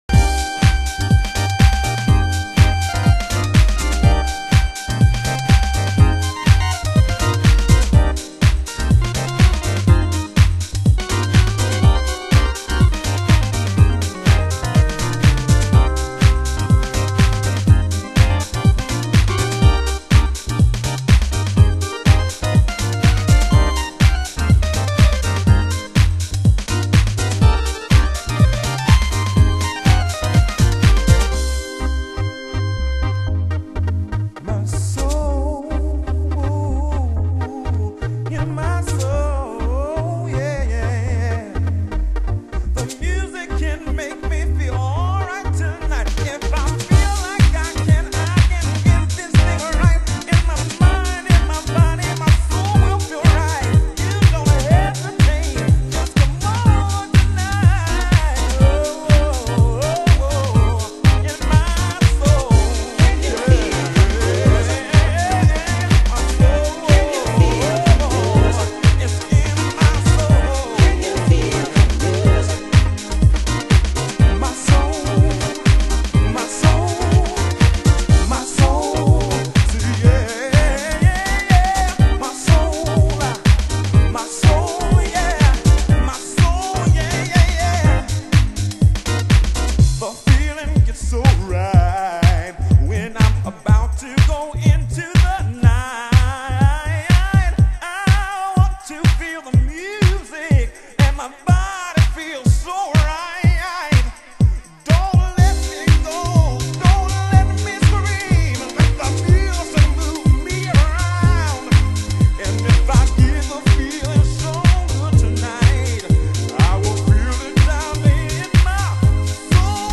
盤質：少しチリパチノイズ